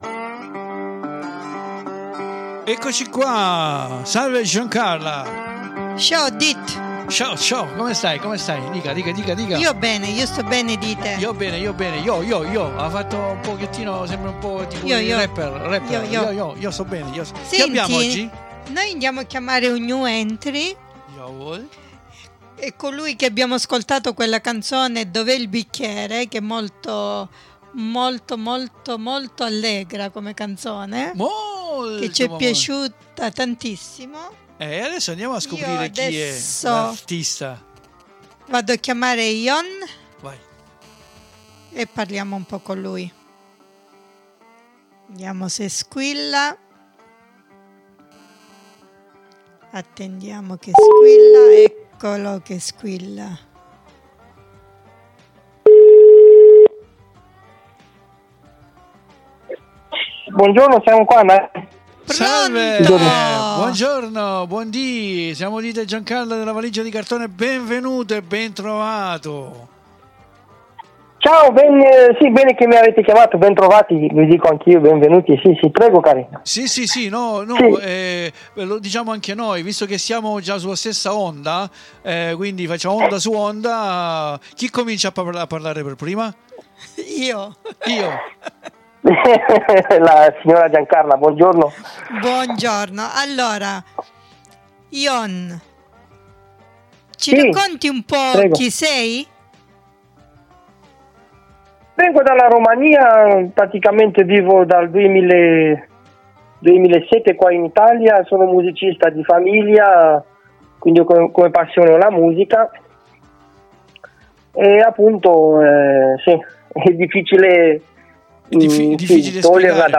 SIMPATICO E GRADEVOLE AL TELEFONO GLI CHIEDIAMO DEL BRANO" DOV'É IL BICCHERE"!